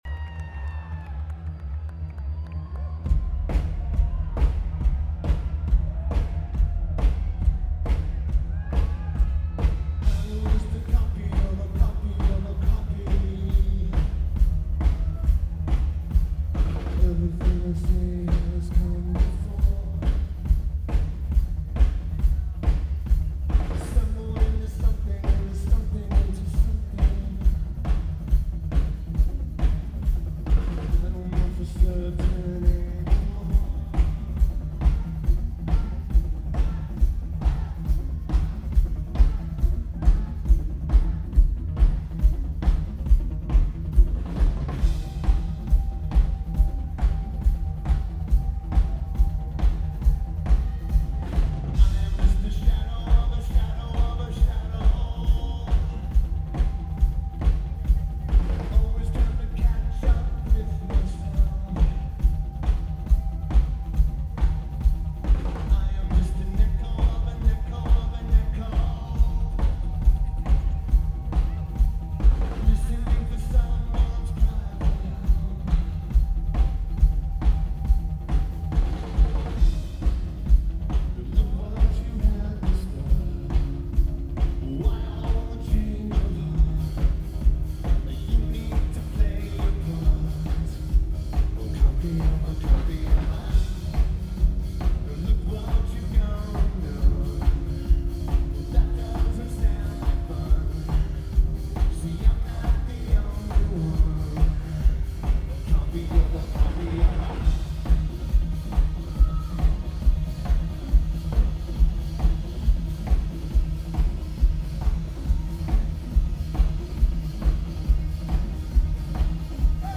Santa Barbara Bowl
Drums
Guitar
Lineage: Audio - AUD (SP-CMC-4U + SP-SPSB-10 + Sony PCM-A10)